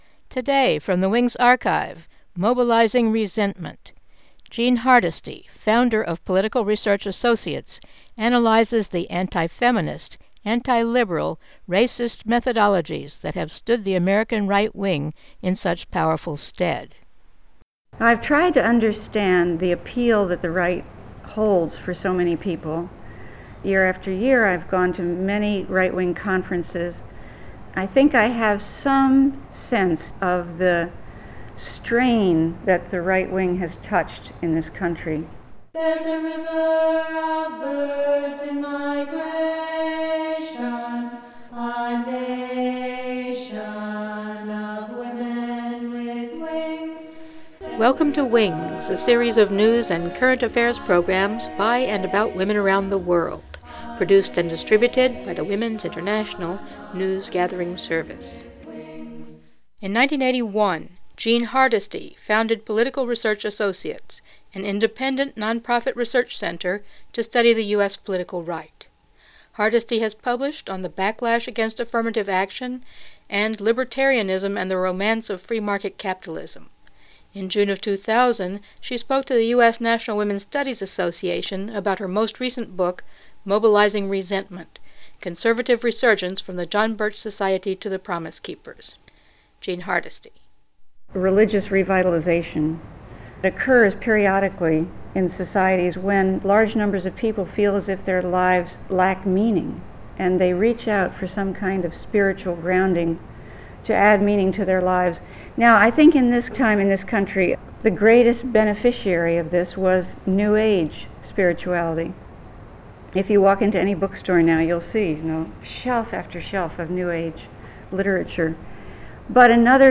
Talk to the US National Women's Studies Association